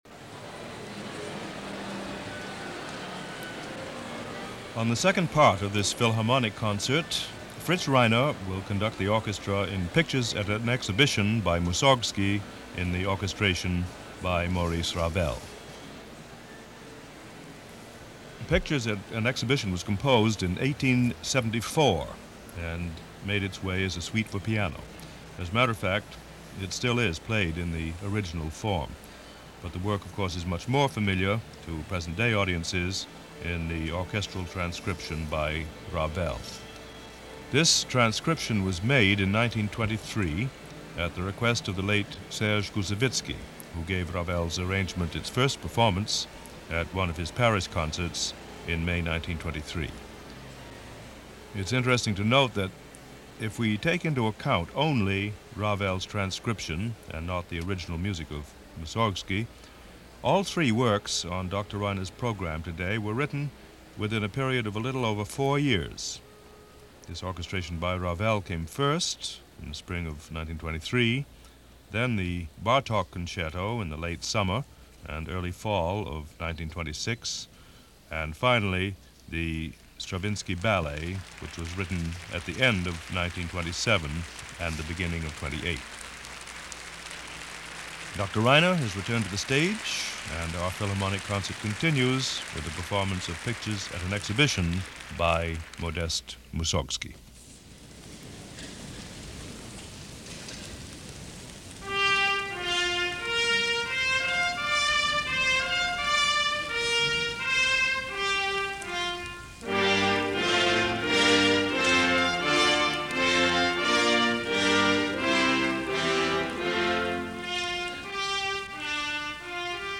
Tonight, it’s just a great performance by the New York Philharmonic under guest conductor Fritz Reiner in a full-blown/turbo-charged performance of Mussorgsky’s Pictures At An Exhibition.
The level of musicianship and ensemble playing was absolutely top-notch. Plus the sound of Carnegie Hall and the excellent work of the CBS engineers (as well as Columbia Records engineers) made these broadcasts and the commercial recordings some of my favorites.